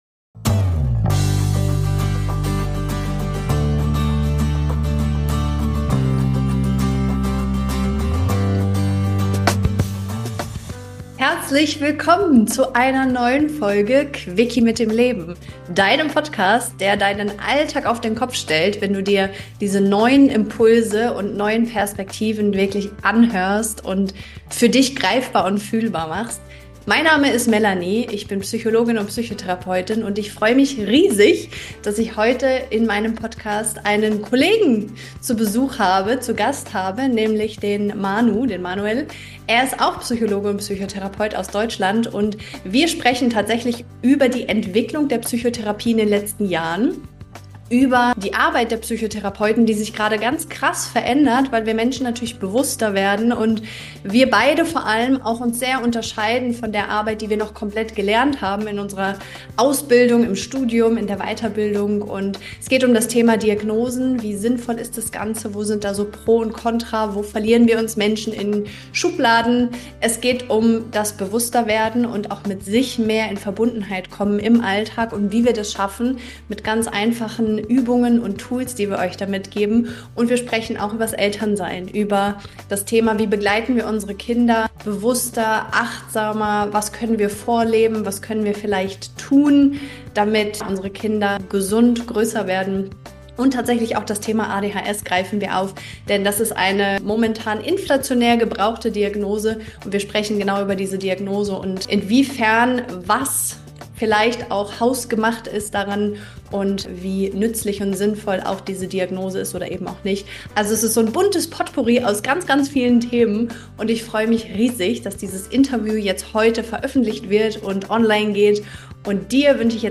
Du erfährst, was Heilung wirklich braucht, jenseits von Diagnosen und Techniken. Dieses Interview inspiriert dich, alte Vorstellungen loszulassen und neue Wege der Selbstverbindung zu gehen.